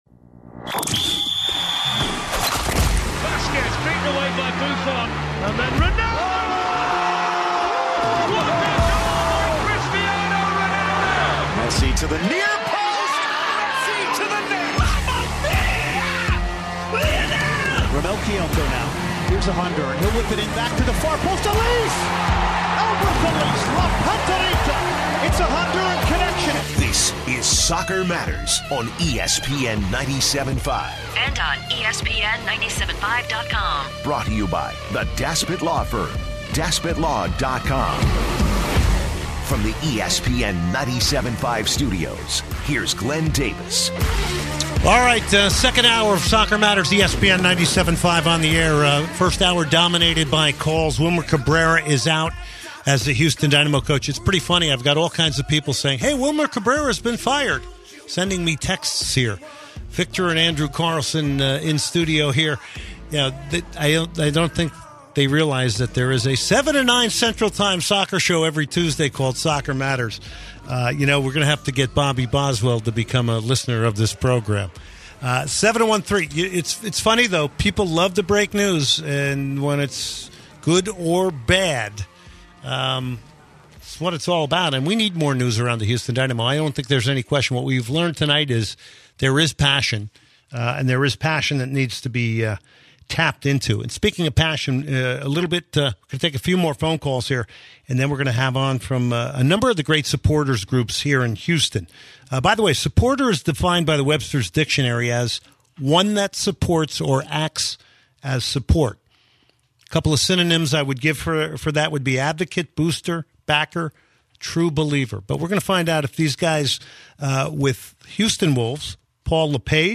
Over the course of the hour the 4 man round-table discusses everything going on with each of their respective clubs heading into the n...